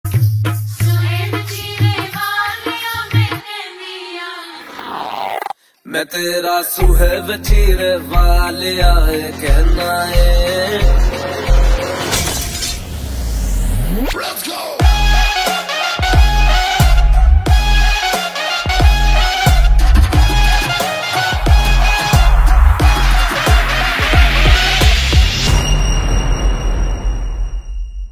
Punjabi Songs
• Simple and Lofi sound
• Crisp and clear sound